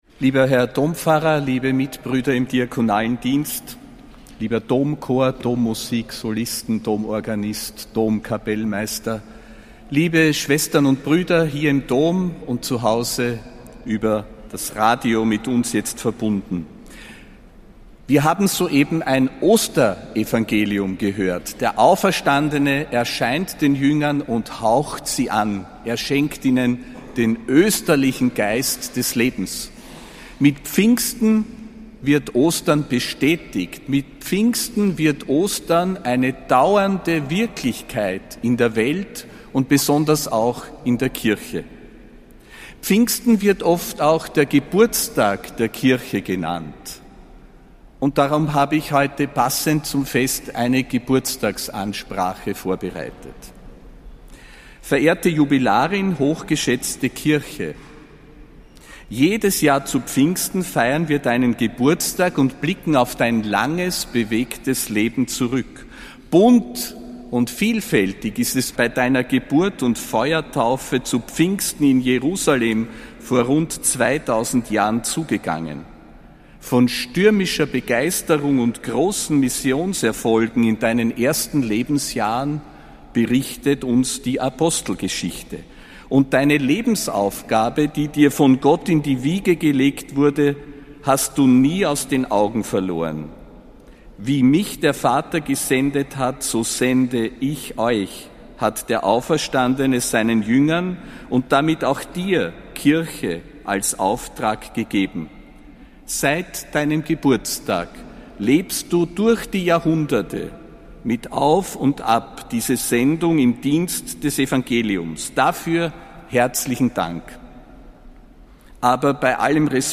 Predigt von Josef Grünwidl zum Pfingstsonntag (29. Mai 2025)
Predigt des Apostolischen Administrators Josef Grünwidl zum